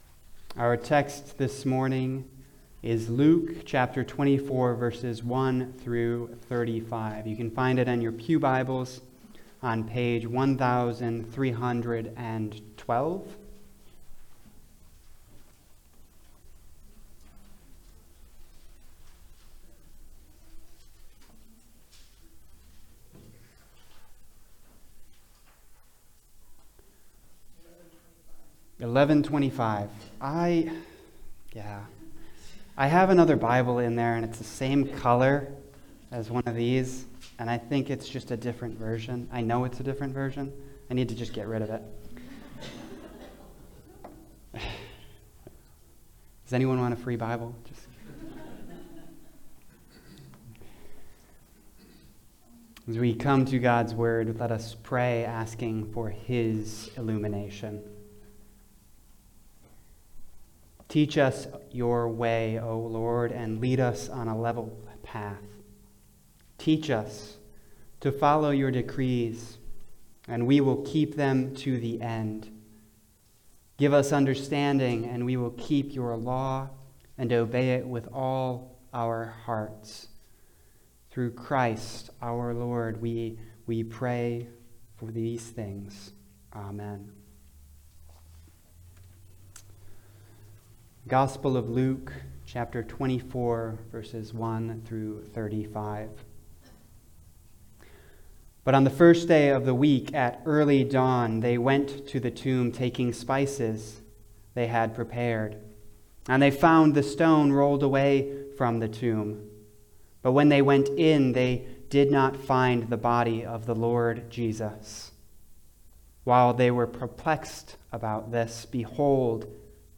Passage: Luke 24:1-35 Service Type: Sunday Service